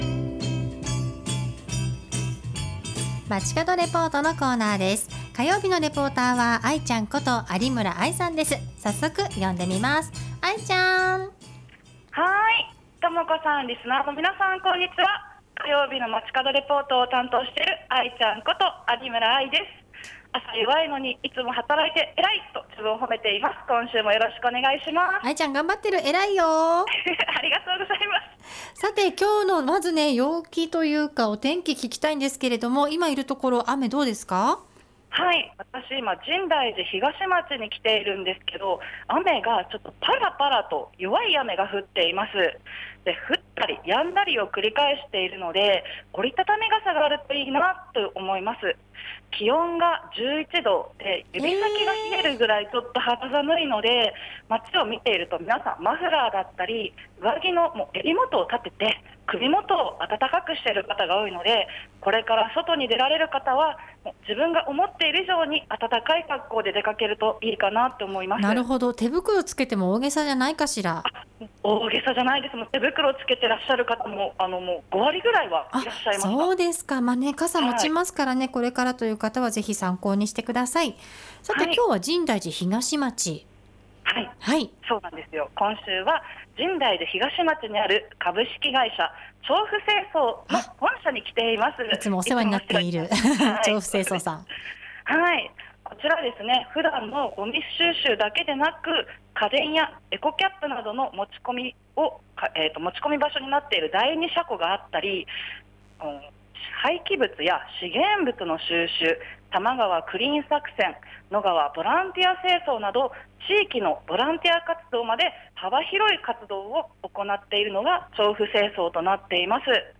今週は私たちがいつもお世話になっている、調布清掃からお届けしました！